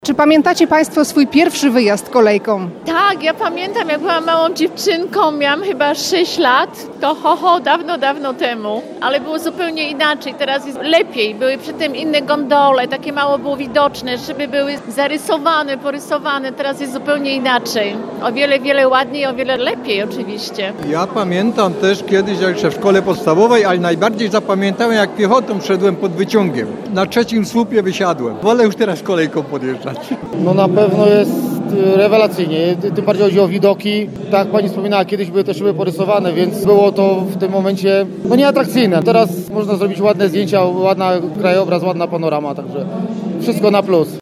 Przybyłych na urodzinową imprezę zapytaliśmy czy pamiętają swój pierwszy wyjazd kolejką.